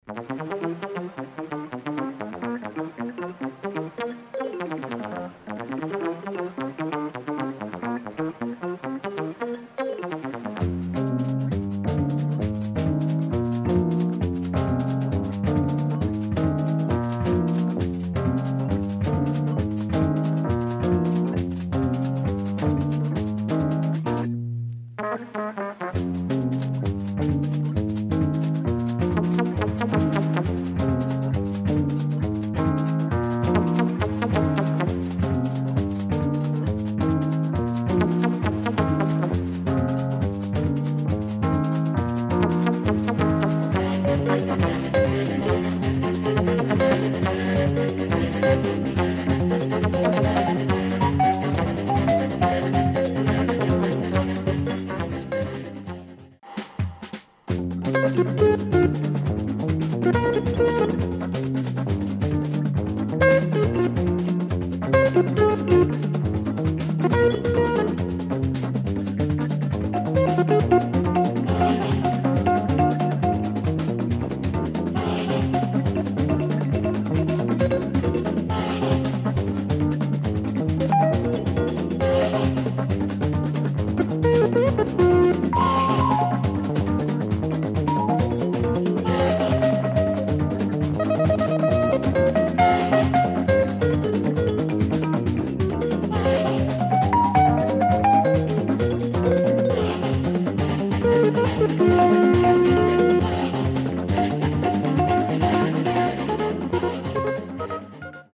electro jazz samba
Hungarian fusion group
Funk , Jazz , Latin